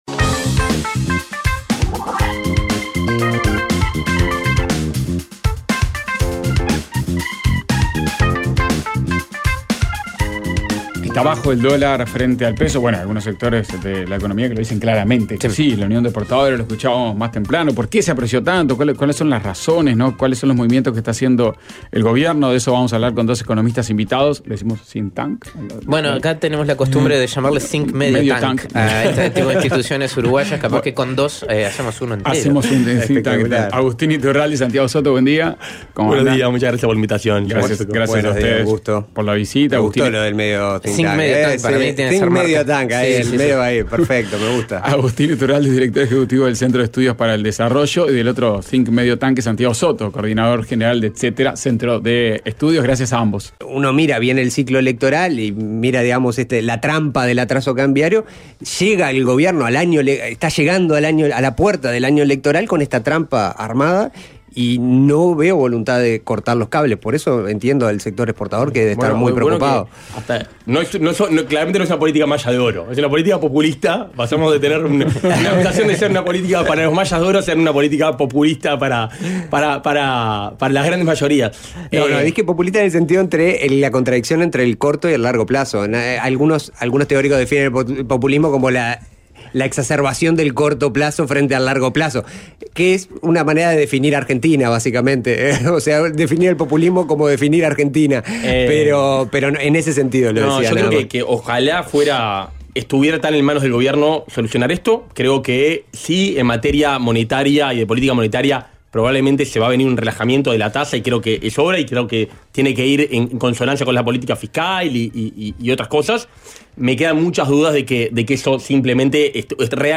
Fragmento de la ronda con los economistas